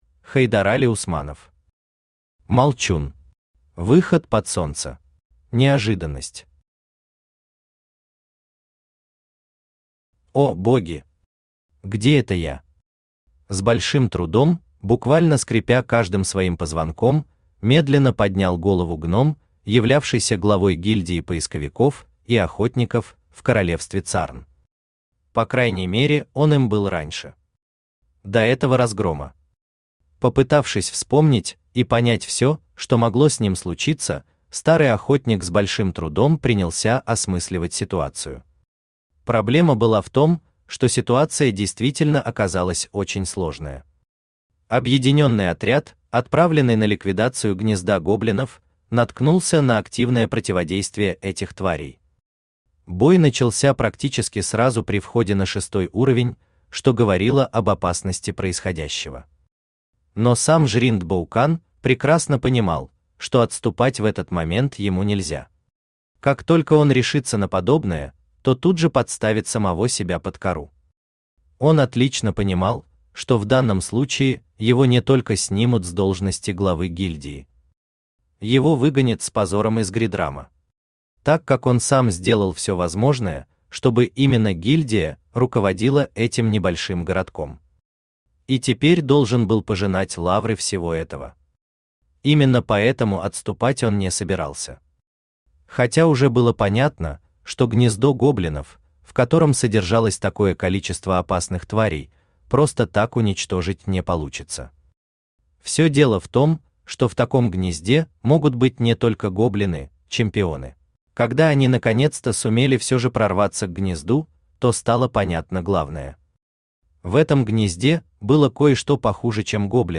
Aудиокнига Молчун. Выход под солнце Автор Хайдарали Усманов Читает аудиокнигу Авточтец ЛитРес.